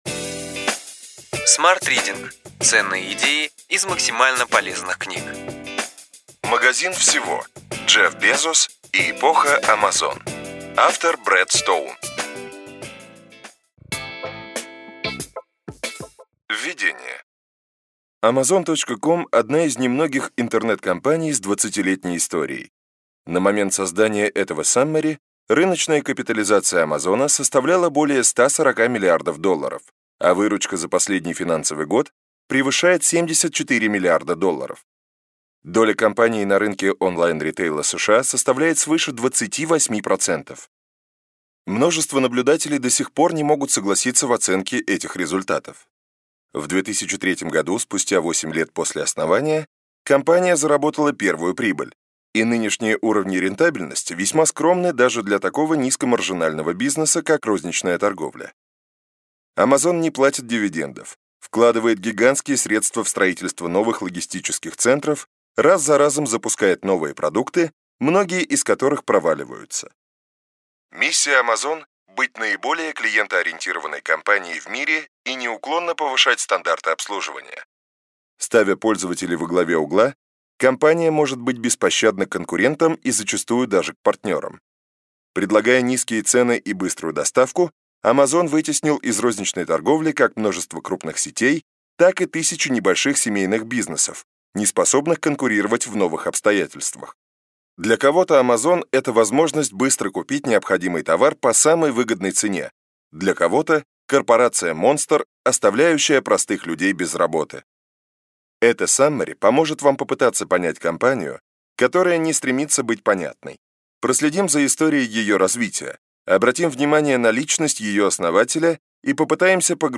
Аудиокнига Ключевые идеи книги: Магазин Всего: Джефф Безос и эпоха Amazon.